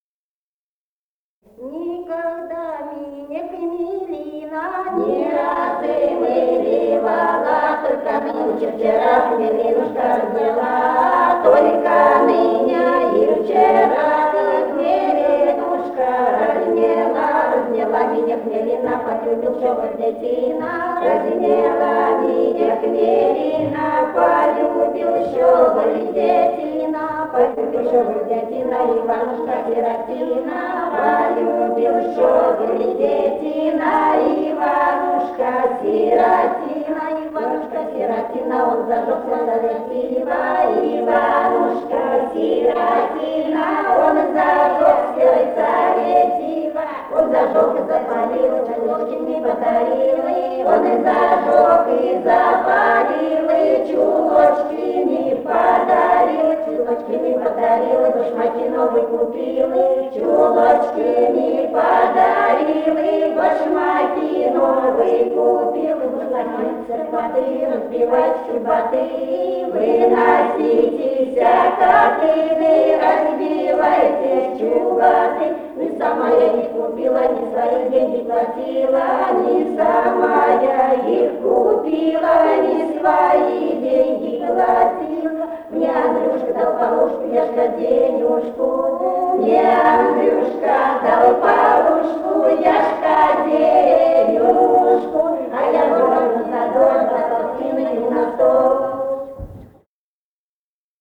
Самарская область, с. Печинено Богатовского района, 1972 г. И1318-25